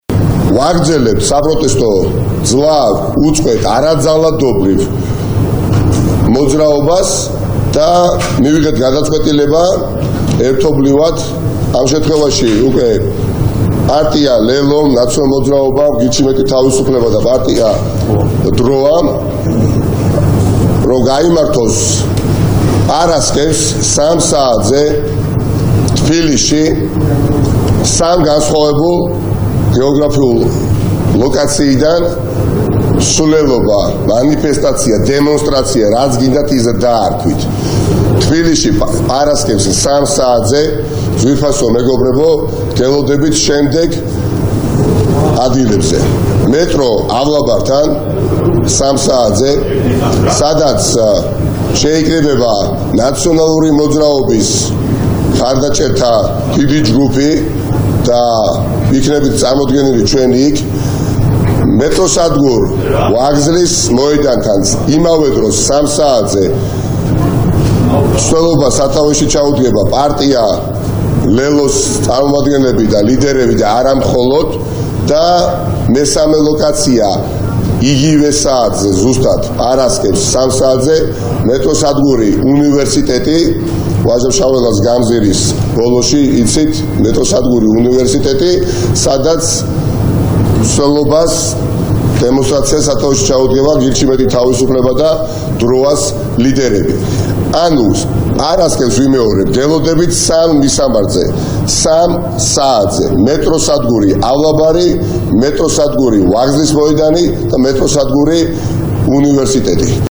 ნიკა მელიას ხმა